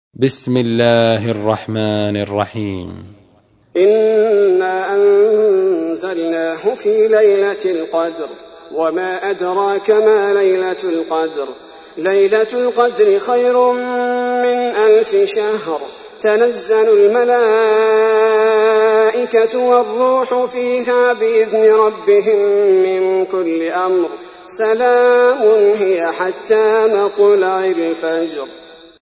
تحميل : 97. سورة القدر / القارئ عبد البارئ الثبيتي / القرآن الكريم / موقع يا حسين